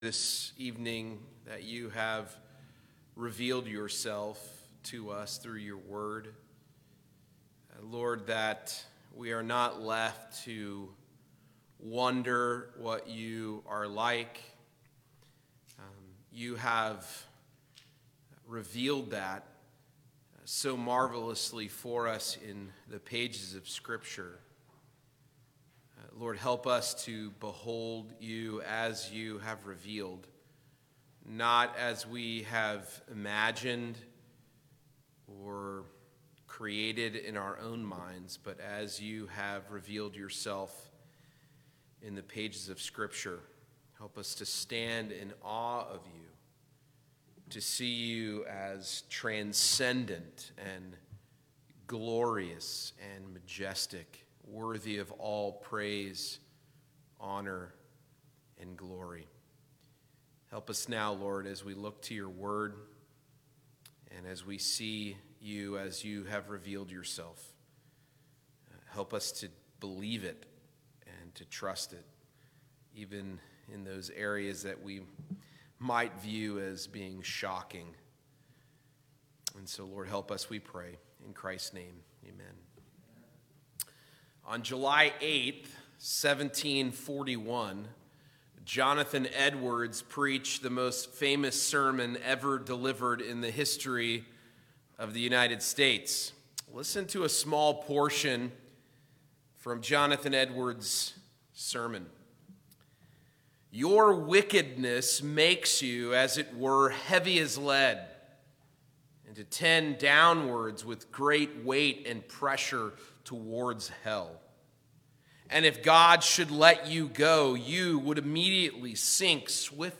2021 The Wrath of God Preacher